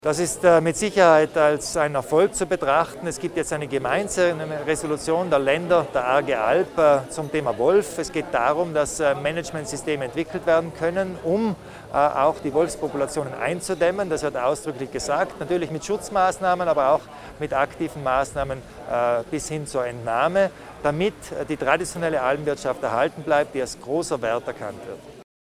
Landehauptmann Kompatscher über die Resolution zur Regulierung der Wolfspopulation in der ARGE ALP